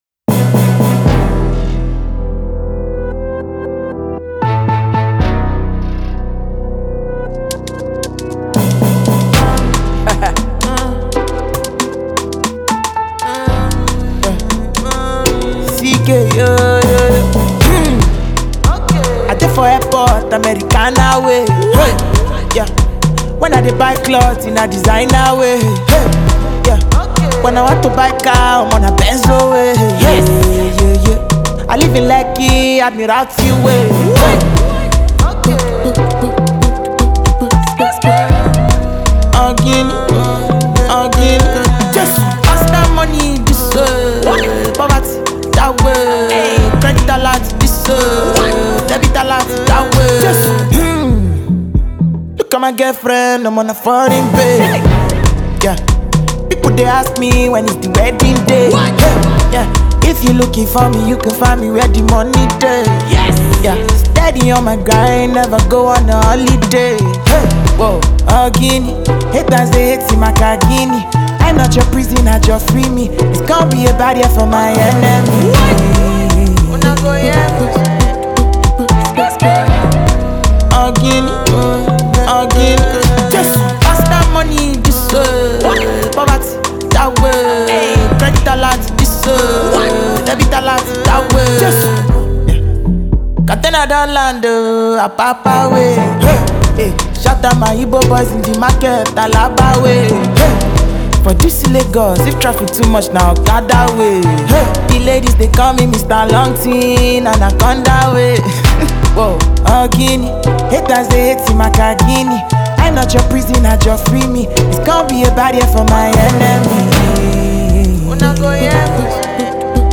A certain club hit